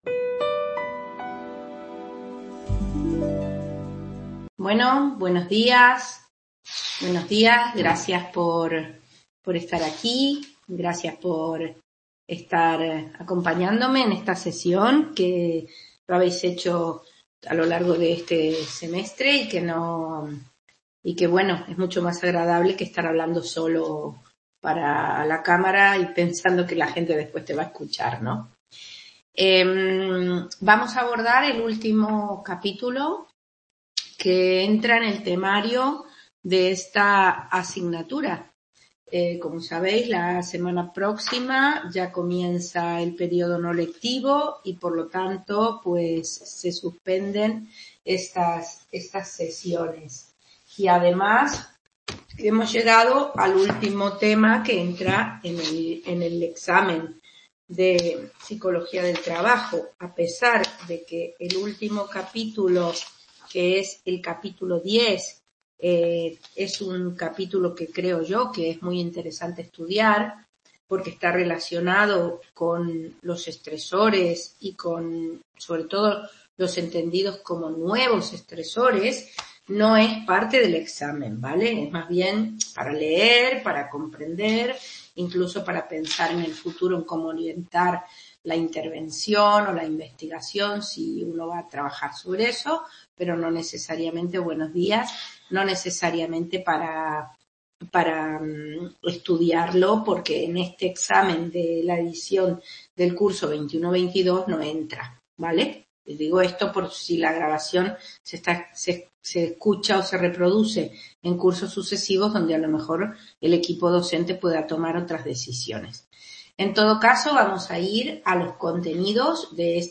Equilibrio familia trabajo Description Tutoría del tema 9 del manual de Psicología del Trabajo.